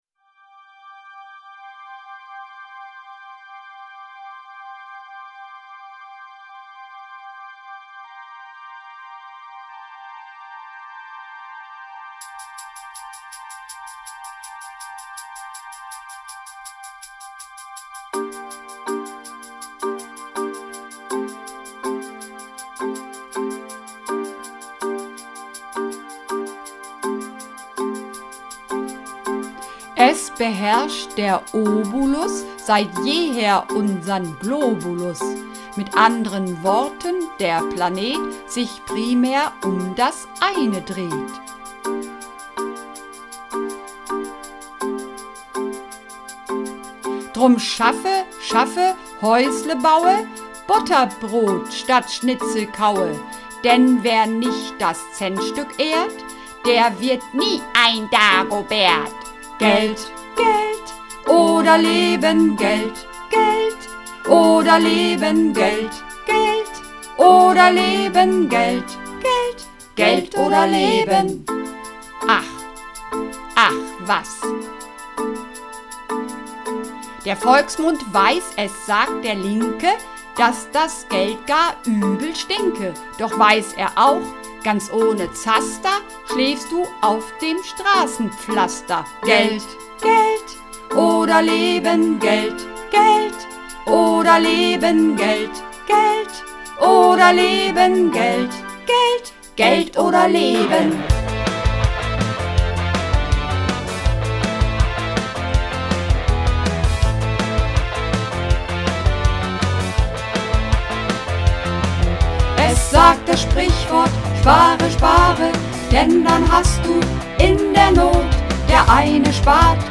Übungsaufnahmen - Geld oder Leben
Runterladen (Mit rechter Maustaste anklicken, Menübefehl auswählen)   Geld oder Leben (Mehrstimmig)
Geld_oder_Leben__4_Mehrstimmig.mp3